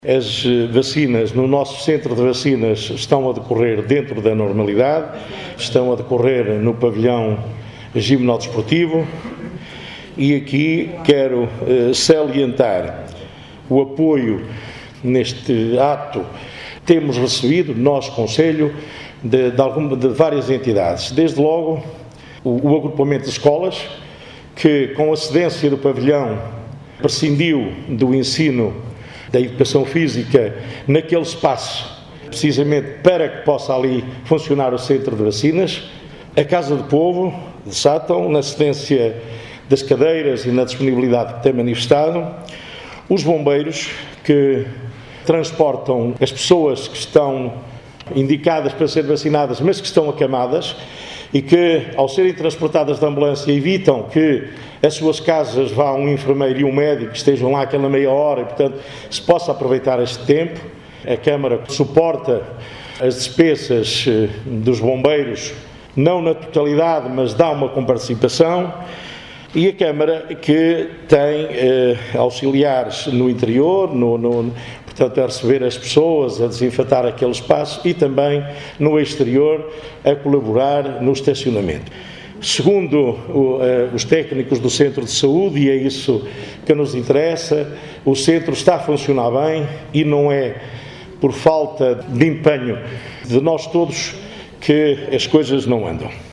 Paulo Santos, presidente da Câmara de Sátão, fez o ponto de situação do plano de vacinação no concelho e as entidades que estão envolvidas aos deputados da Assembleia Municipal.